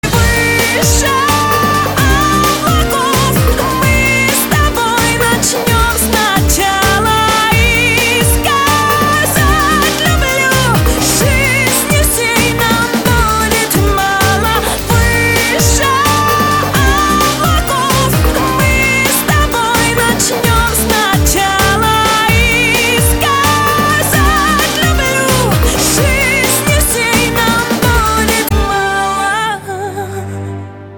• Качество: 256, Stereo
поп
громкие
женский вокал